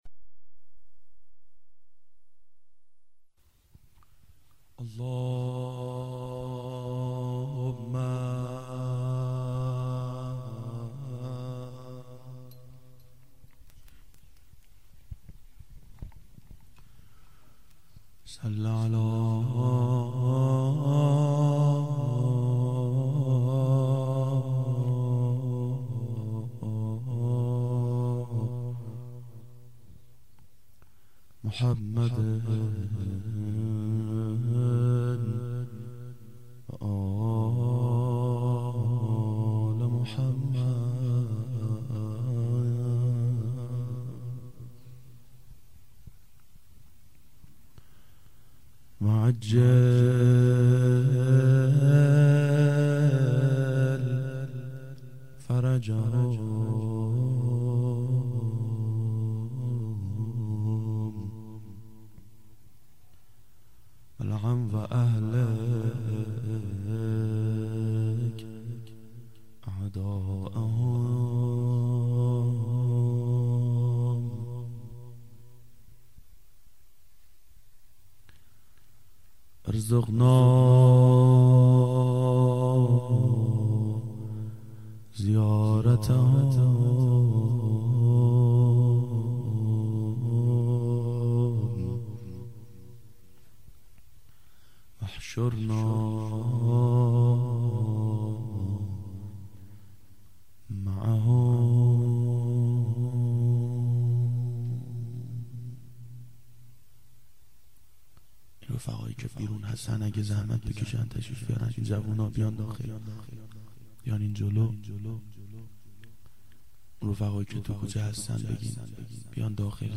مناجات با امام زمان و روضه امام حسن علیهماالسلام
گلچین سال 1389 هیئت شیفتگان حضرت رقیه سلام الله علیها